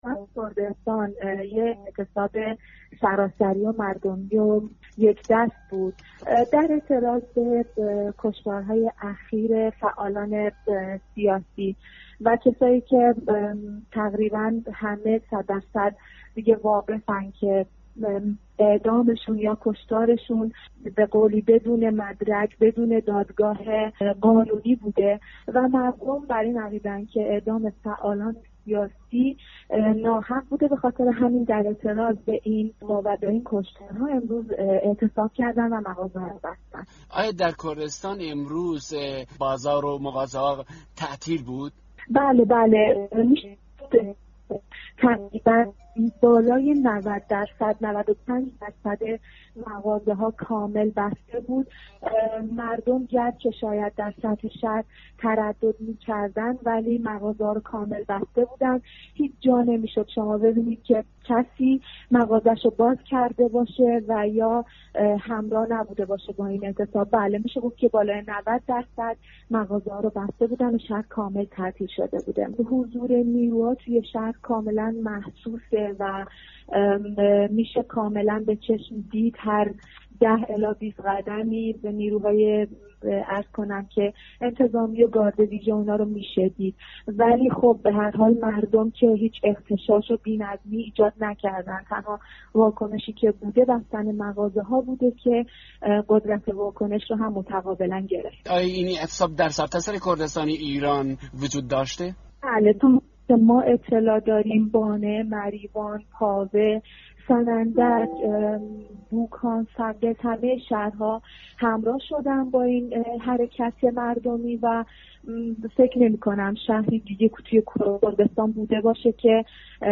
گفت‌وگوی رادیوفردا با یک فعال مدنی در شهر سنندج